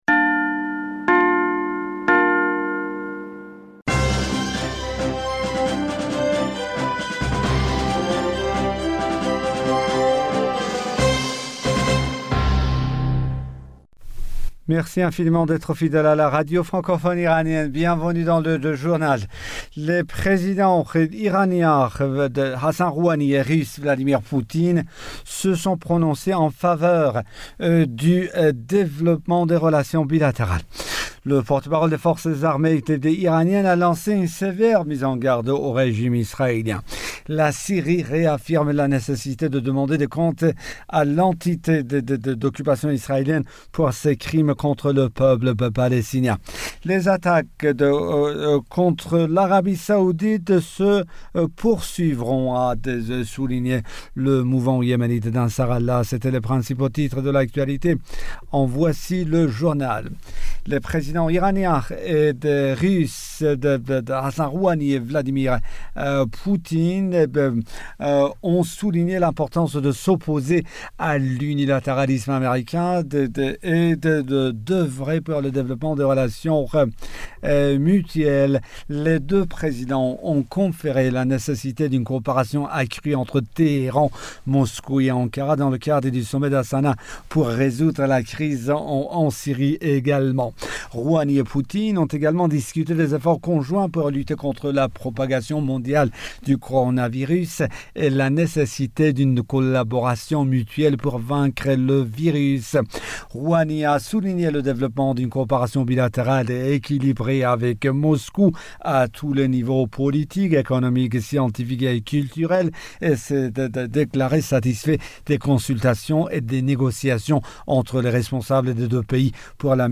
Bulletin d'information du 17 Juillet 2020